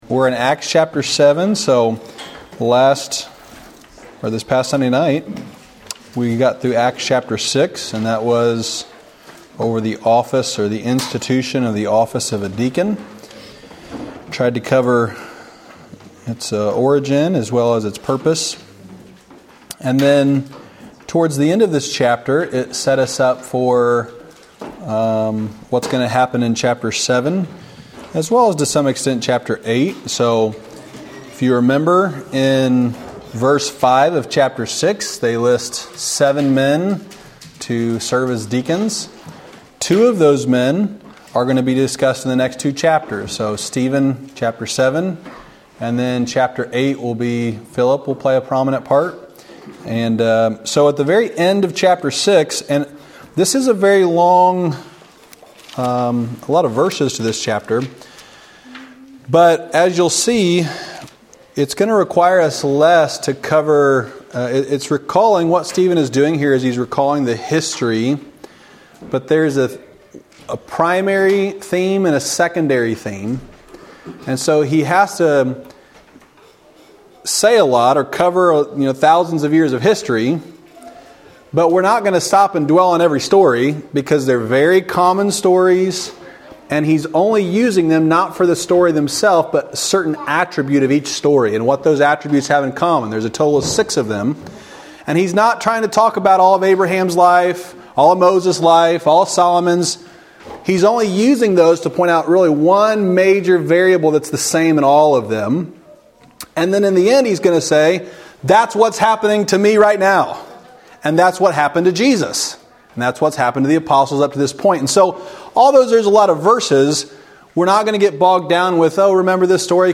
"Acts Chapter 7" The twelfth lesson in an expository series through the book of Acts.
Wednesday night lesson from October 25, 2023 at Old Union Missionary Baptist Church in Bowling Green, Kentucky.